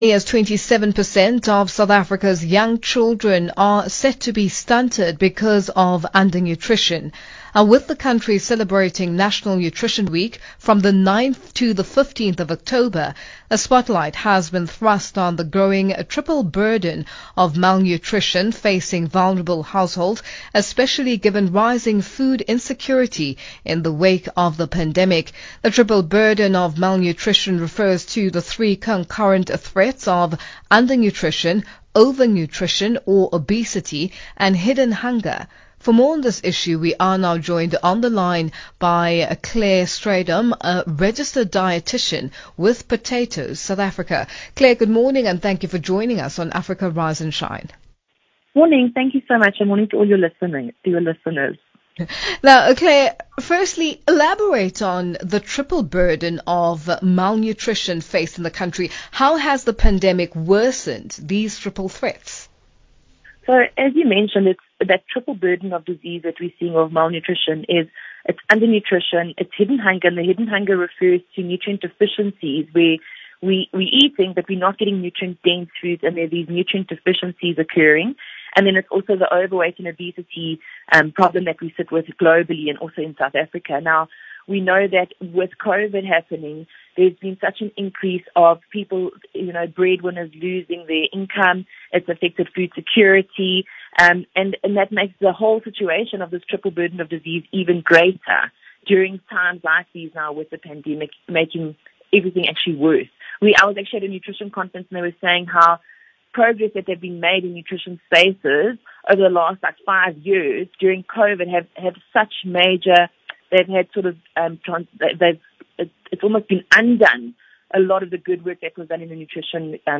Channel Africa interview from 15 October 2021, click here.